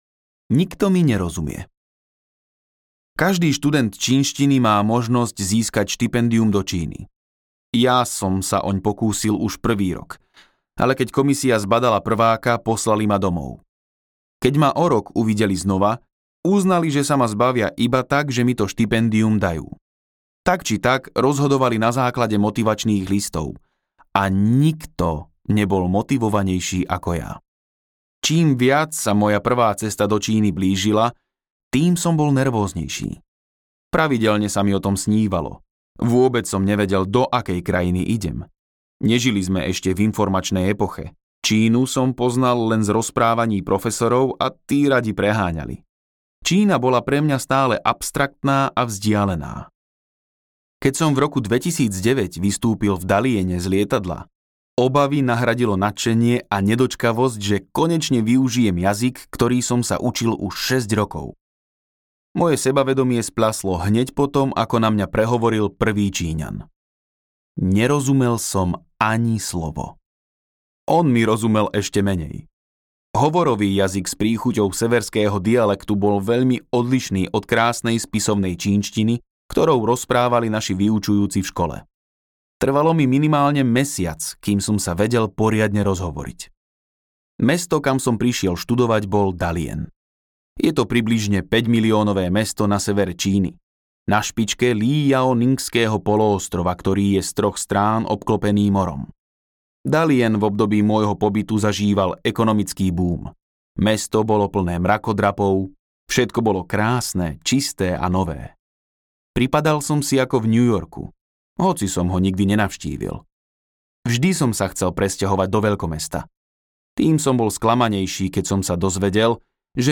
Přidat do wishlistu audiokniha Příběhy Autor